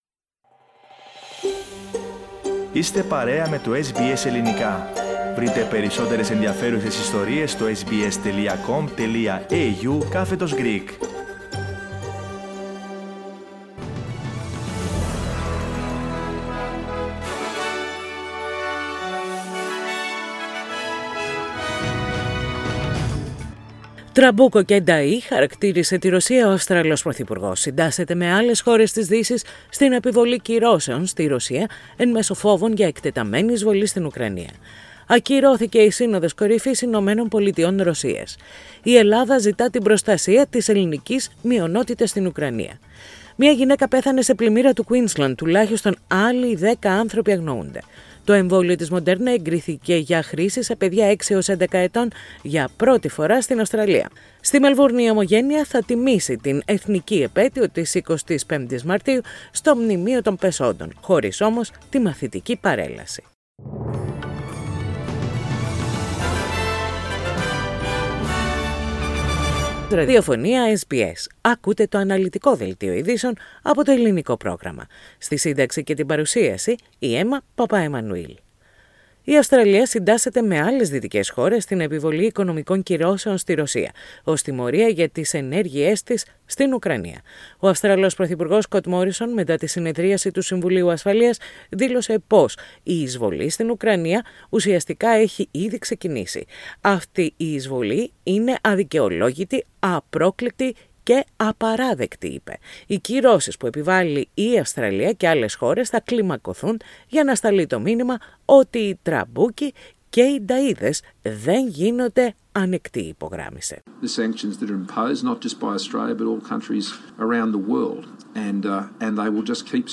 The detailed bulletin, with the main news from Australia, Greece, Cyprus and the international news.